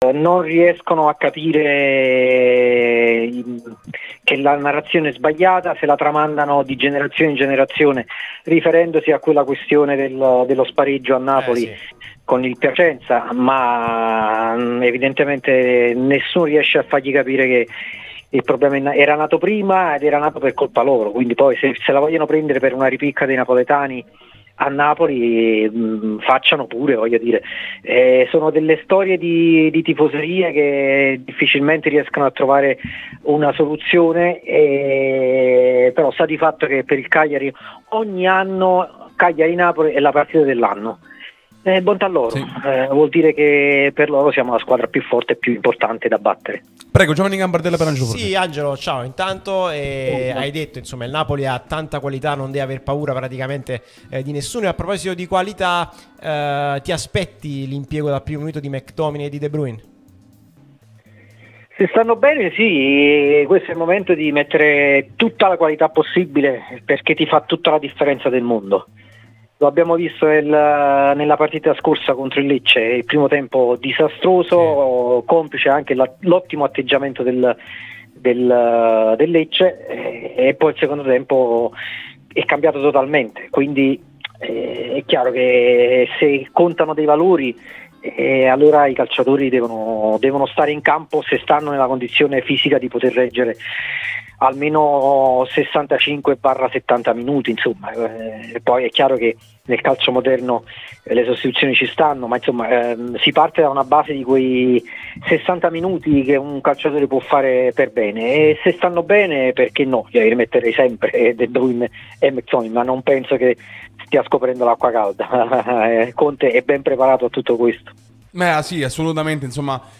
giornalista e scrittore